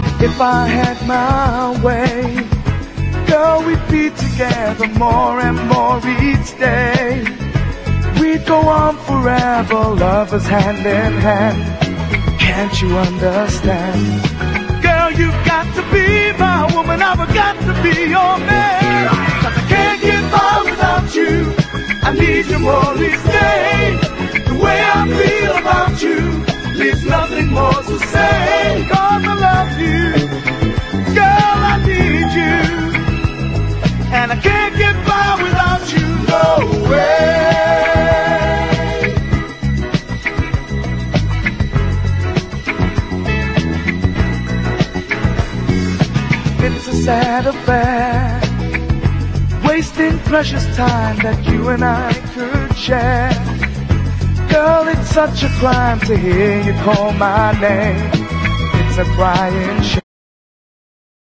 SOUL / SOUL / 70'S～ / DISCO / DANCE CLASSIC
華麗なストリングスと共に体が軽くなるような見事なアレンジは永遠です。